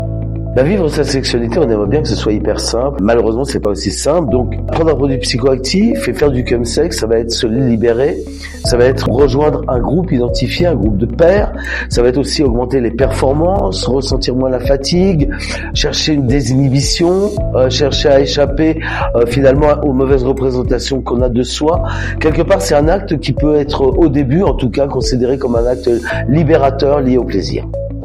2-chemsex-lesmotivations-musique.mp3